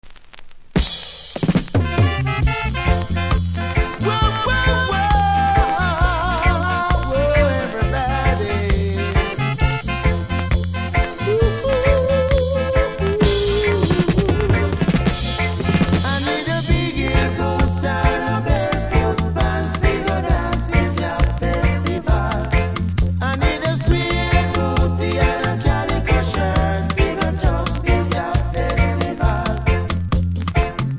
Disco Mix
REGGAE 70'S